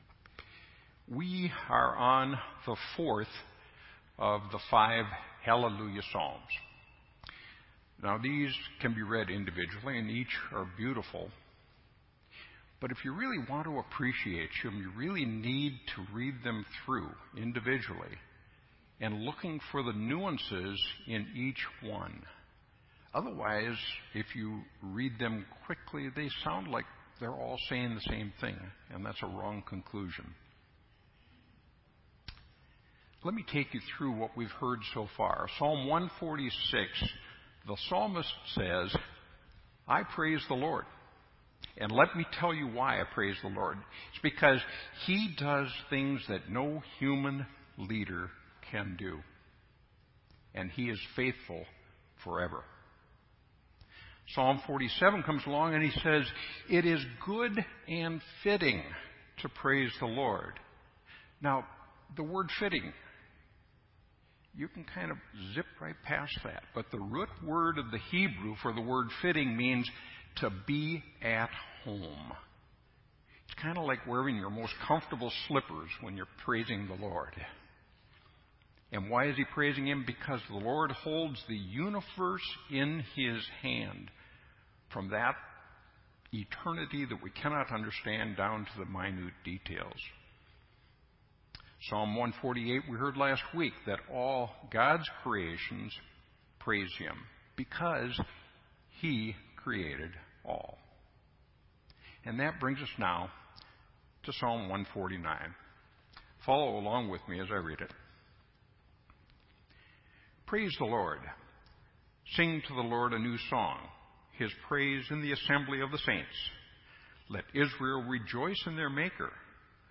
This entry was posted in Sermon Audio on August 31